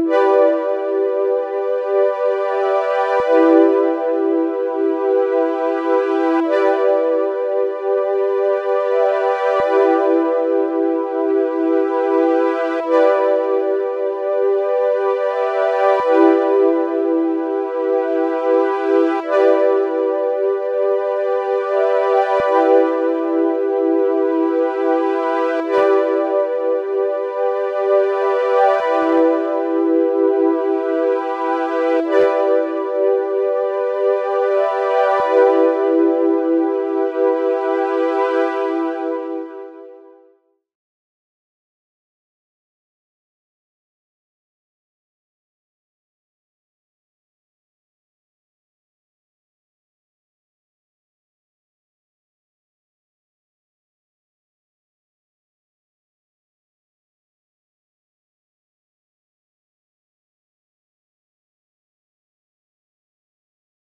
🔹 52 Premium Serum Presets built for synthwave, retro pop, and nostalgic melodic house.
Lush Keys & Warm Pads – Layered textures that glide through the mix